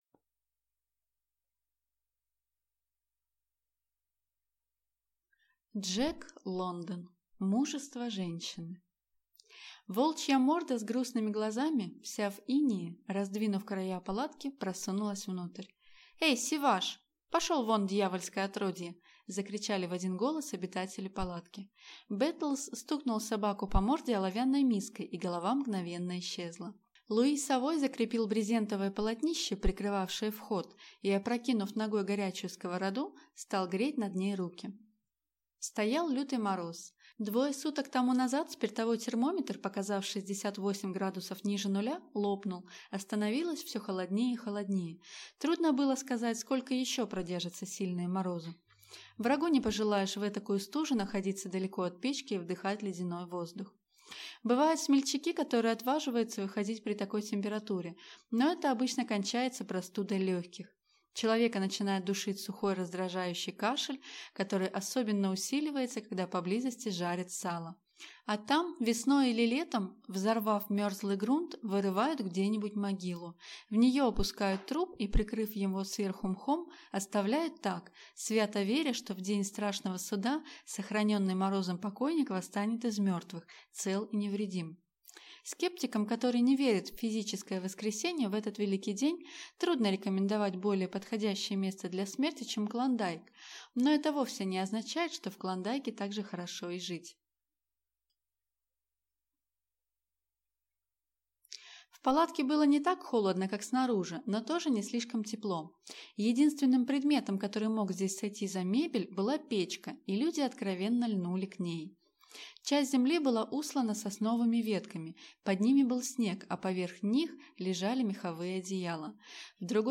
Аудиокнига Мужество женщины | Библиотека аудиокниг